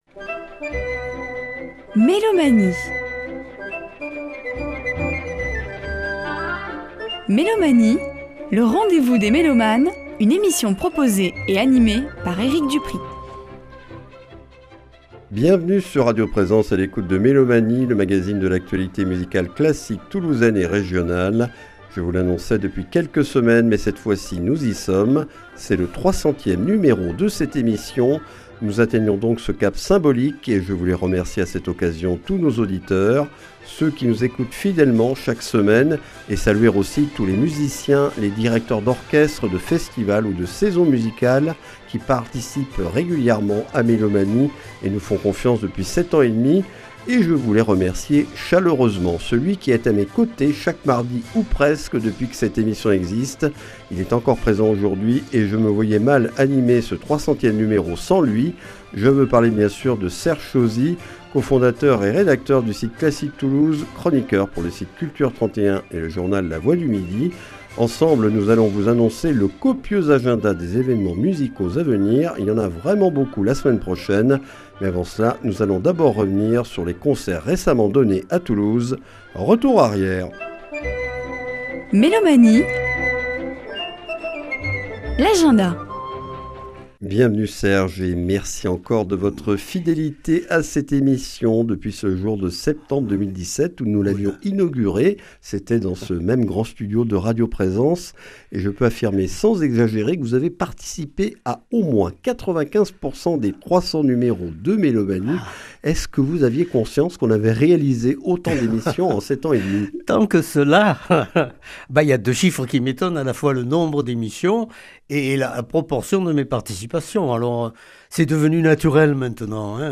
Mélomanie(s) et ses chroniqueurs présentent l'actualité musicale classique de notre région.